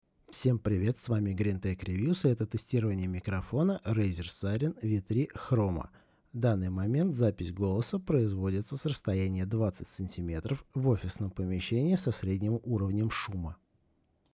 Пример записи голоса можно изучить ниже. Микрофон характерен чистейшей передачей голоса и прекрасно отсекает посторонние звуки.
Спектр записи демонстрирует полное соответствие заявленным производителем характеристик (и даже несколько шире).